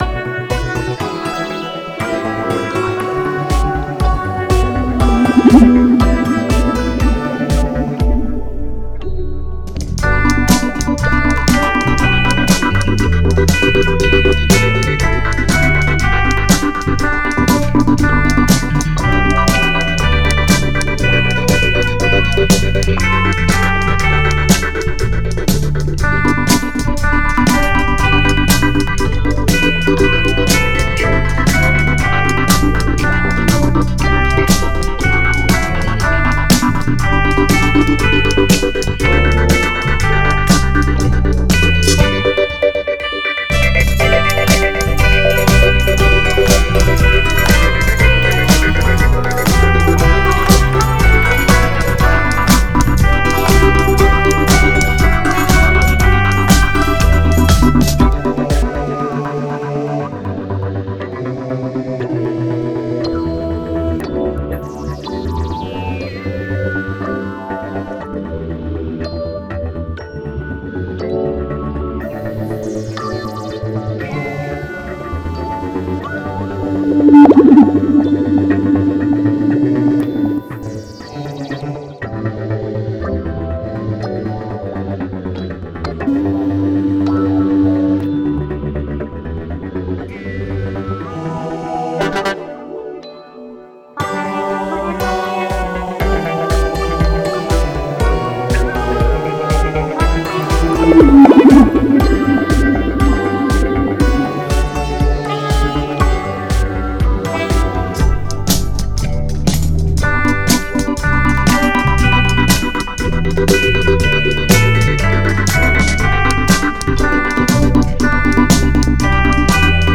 Deux versions instrumentales tirées du site de jp.w